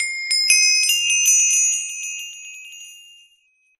old_explode.ogg